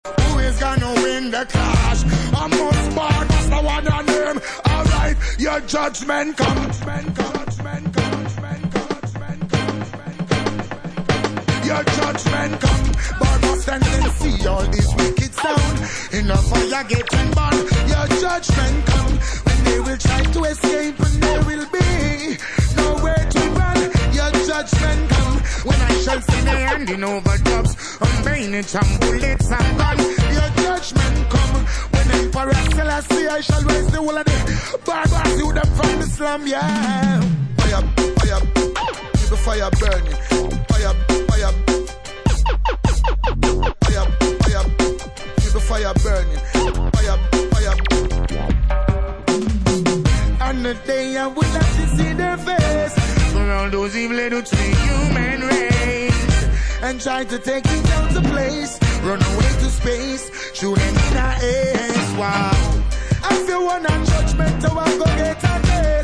UK Garage / Breaks, Hip Hop/Dj Tools